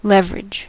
leverage: Wikimedia Commons US English Pronunciations
En-us-leverage.WAV